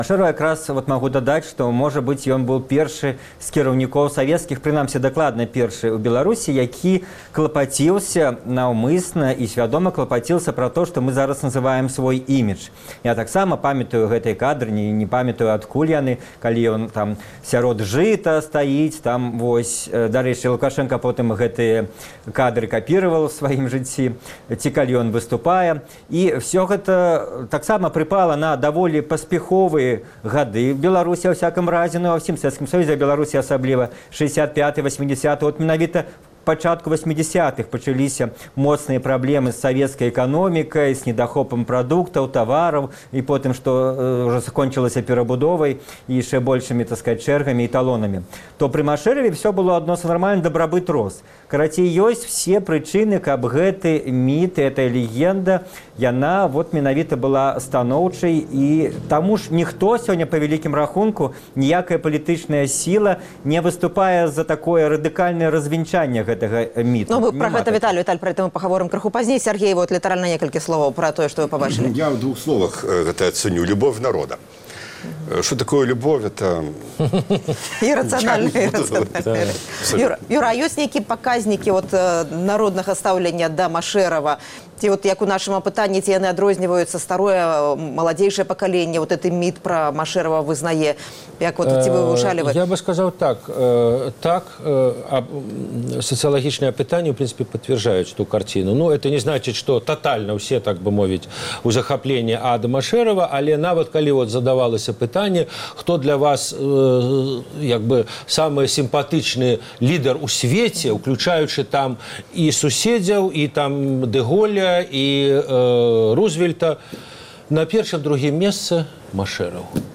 На сувязі з менскай студыі